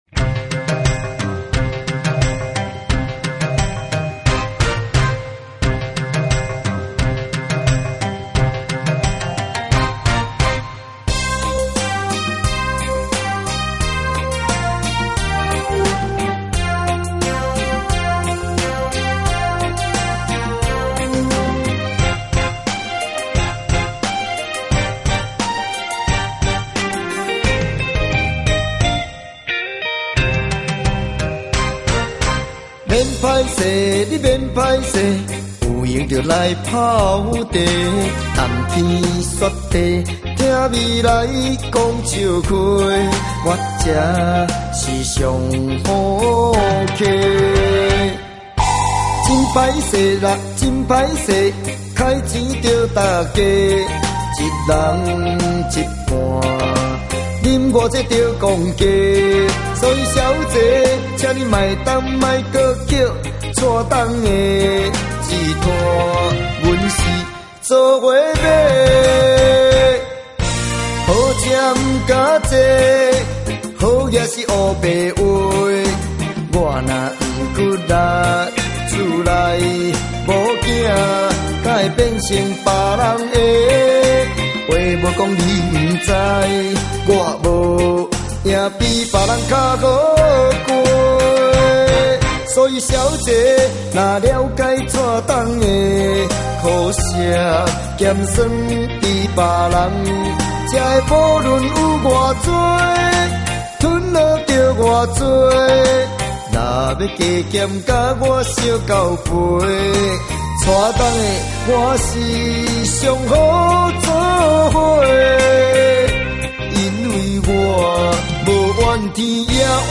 台語歌曲